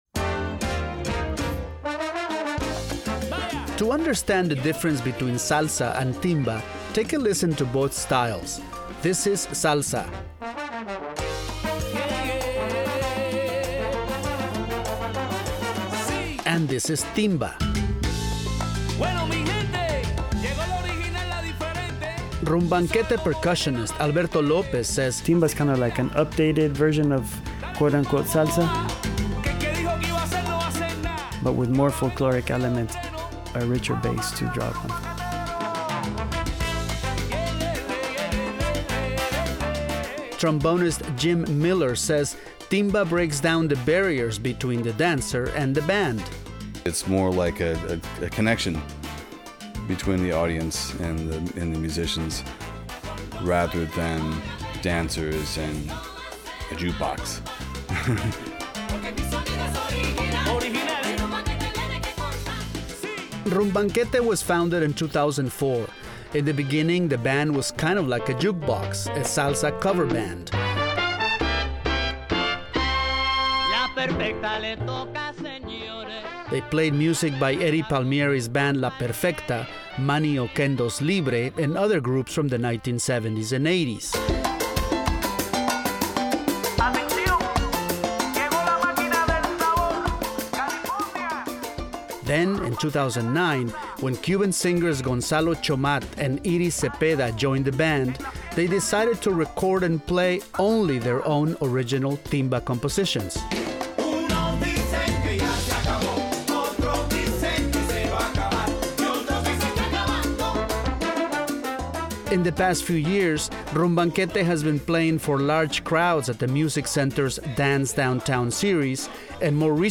In the early 1990s, “Timba”, a new style of dance music from Cuba, began to make its way around the world.
timba.mp3